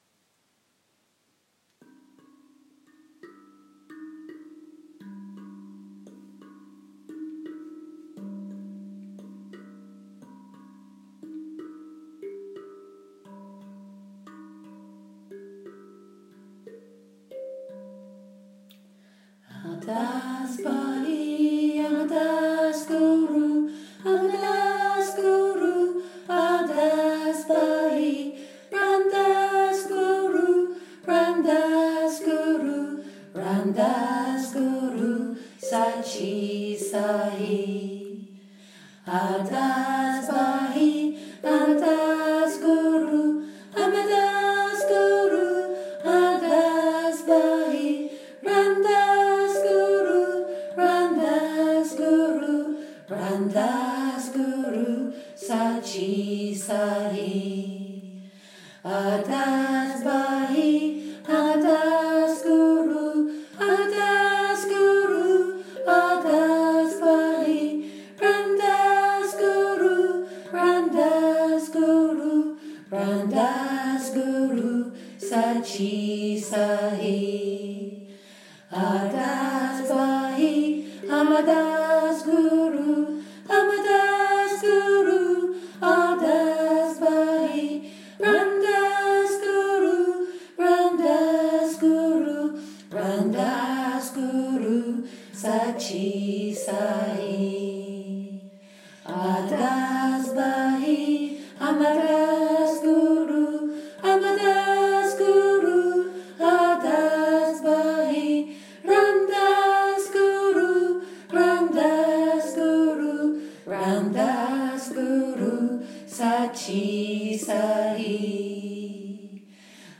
aardas-bhaee-musique.m4a